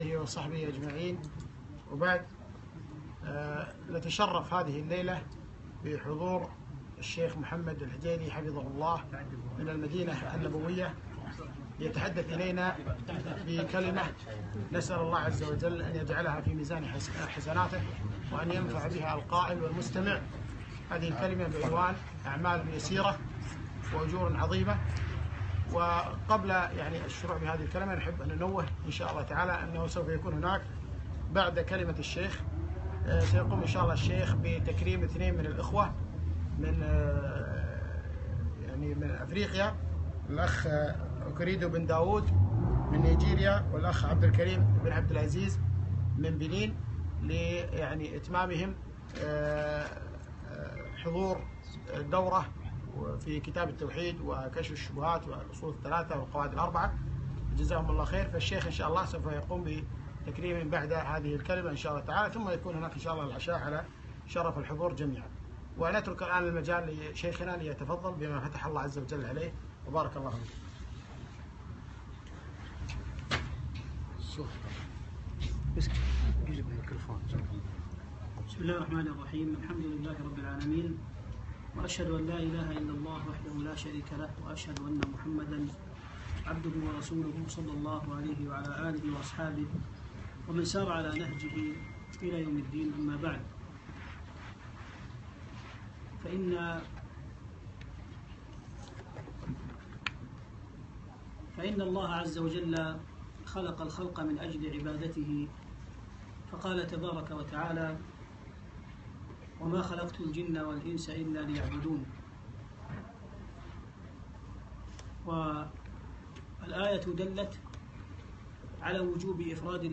أعمال يسيرة بأجور عظيمة - محاضرة